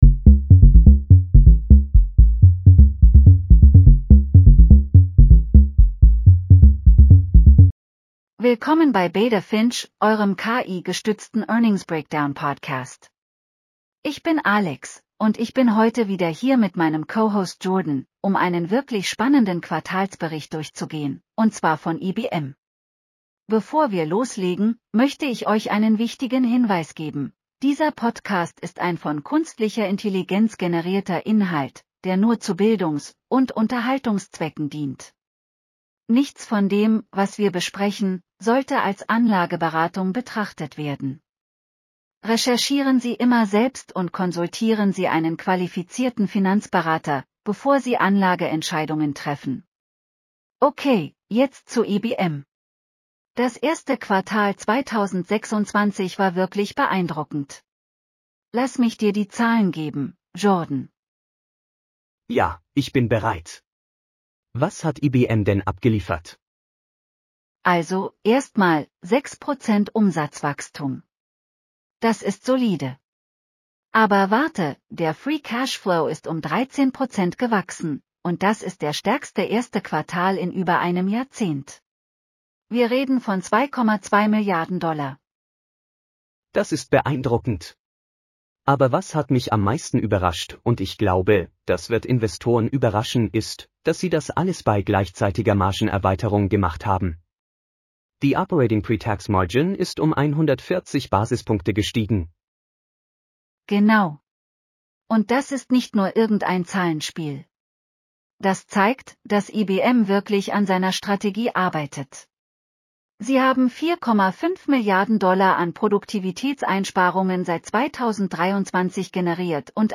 International Business Machines Q1 2026 earnings call breakdown.